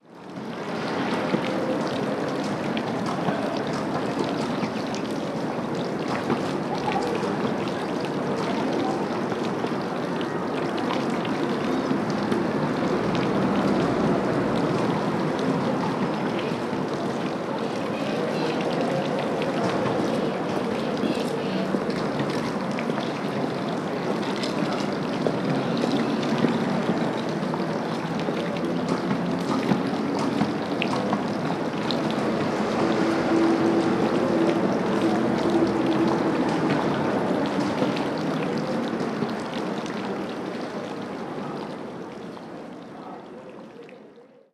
Ambiente de una plaza con una fuente
tráfico
chorro
Sonidos: Agua
Sonidos: Gente
Sonidos: Ciudad